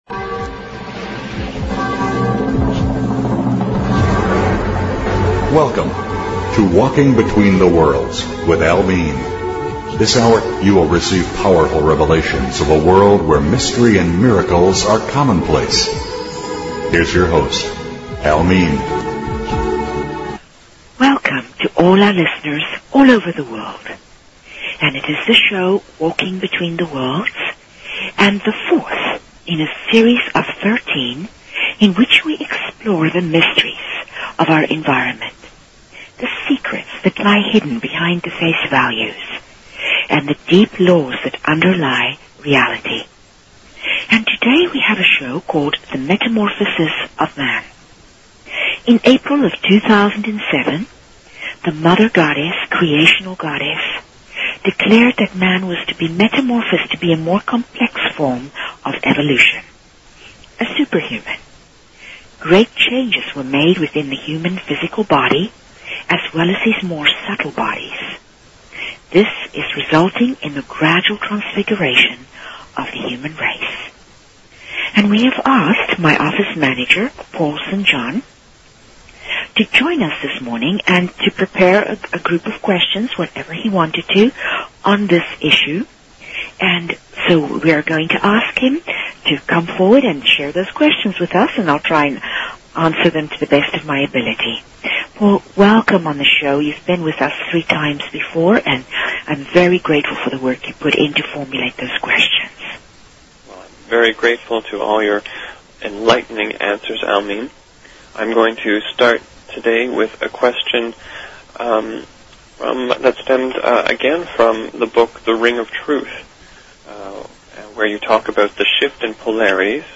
Talk Show Episode, Audio Podcast, Opening_the_Doors_of_Heaven and Courtesy of BBS Radio on , show guests , about , categorized as